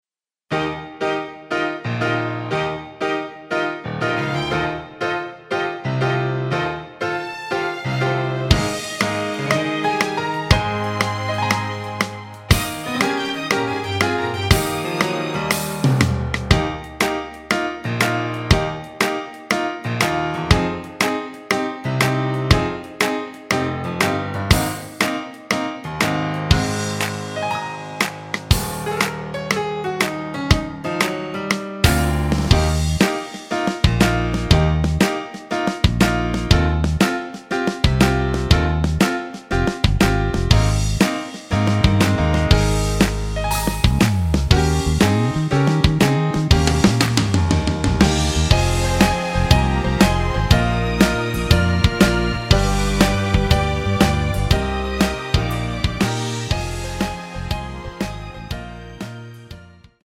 MR입니다.
키 Eb 가수
원곡의 보컬 목소리를 MR에 약하게 넣어서 제작한 MR이며